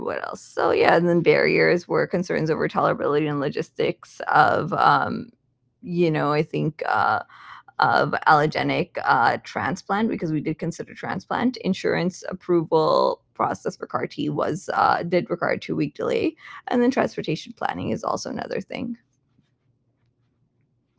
For this pilot study, we recruited 15 hematologist-oncologists to provide detailed patient charts through our conversational patient scribe.